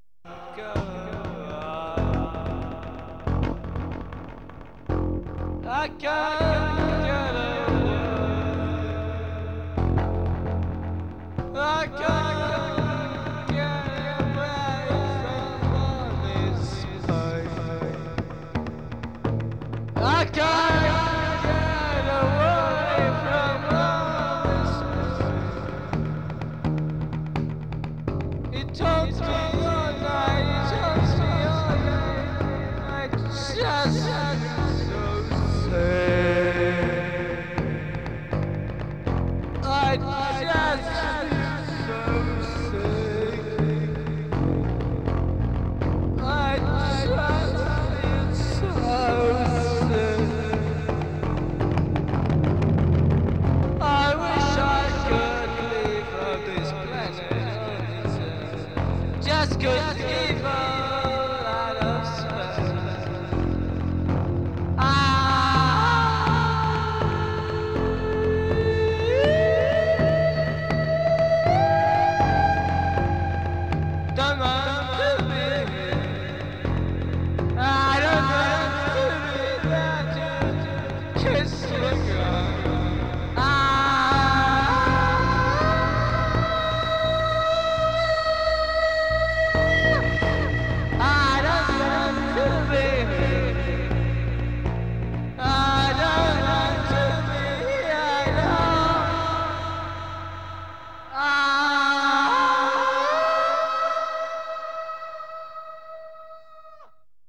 Noch ein düsteres Stück für düstere Tage.